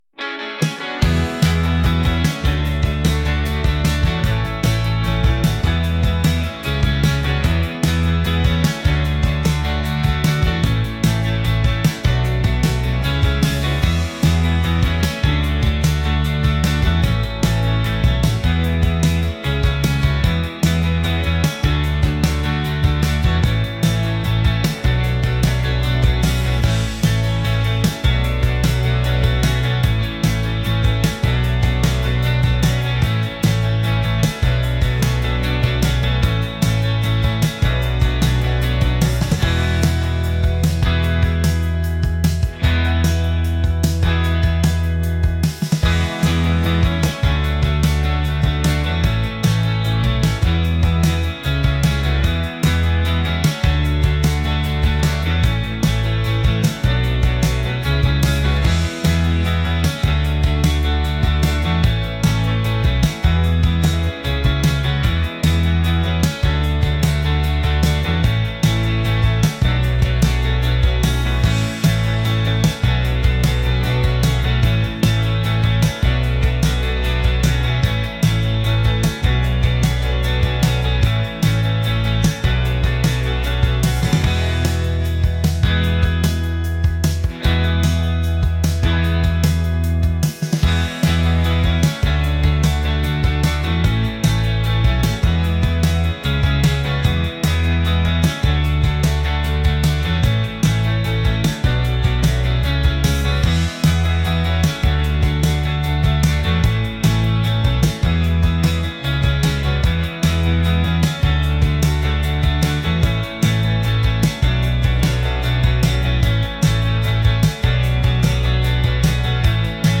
pop | upbeat | indie